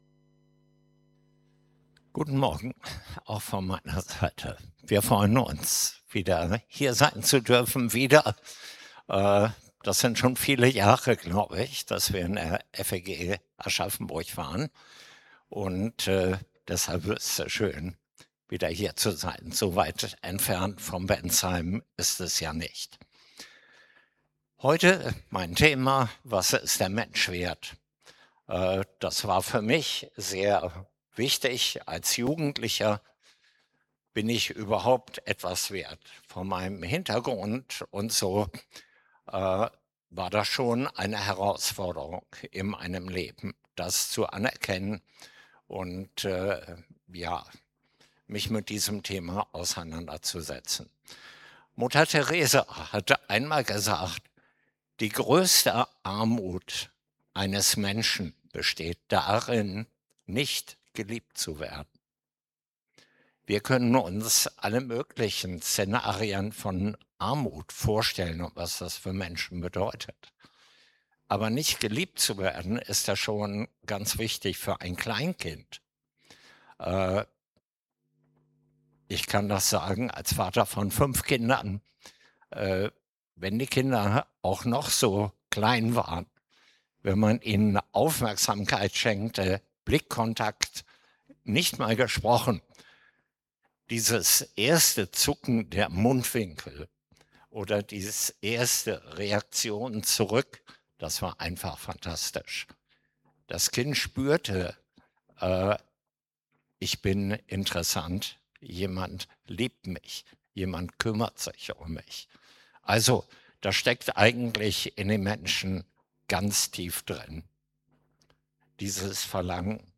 Predigt Podcast